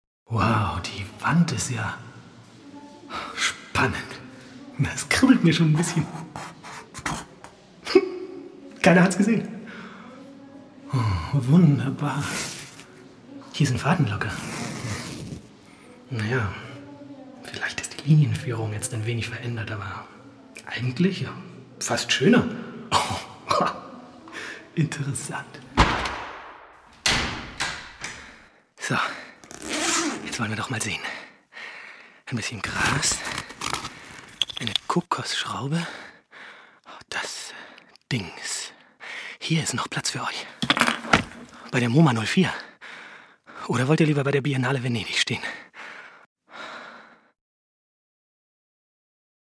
deutscher Sprecher und Schauspieler
schweizerdeutsch
Sprechprobe: eLearning (Muttersprache):